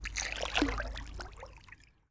water-splash-09
Category 🌿 Nature
bath bathroom bubble burp click drain dribble dripping sound effect free sound royalty free Nature